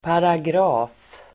Uttal: [paragr'a:f]